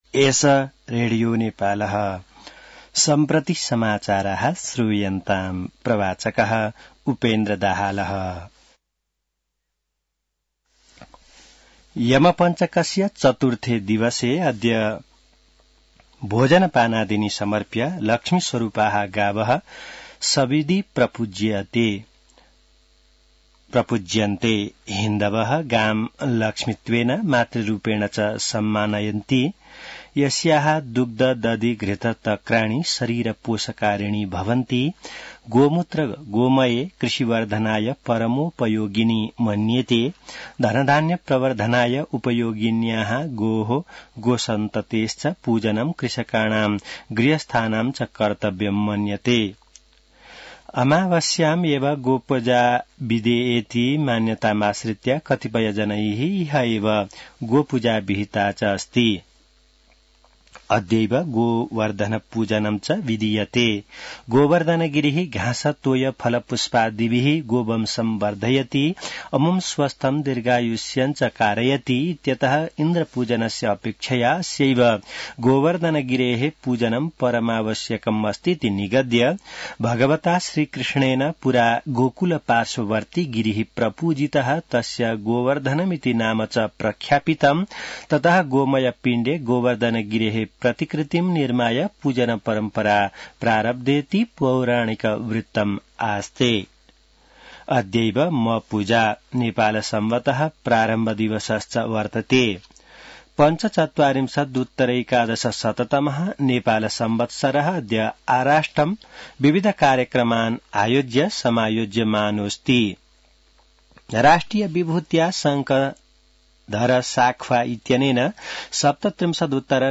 संस्कृत समाचार : १८ कार्तिक , २०८१